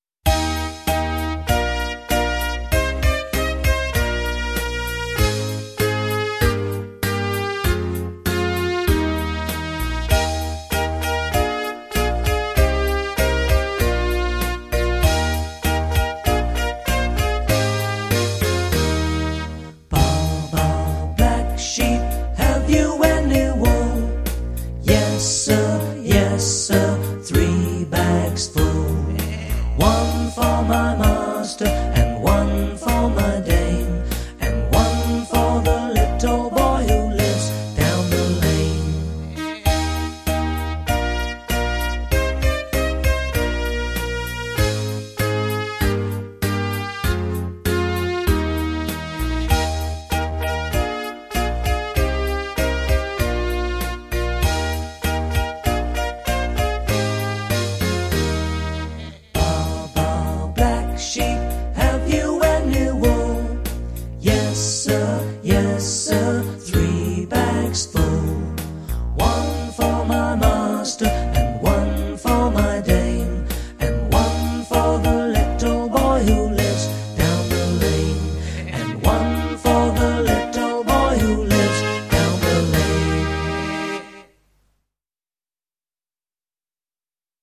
Baa Baa Black Sheep - английская песенка.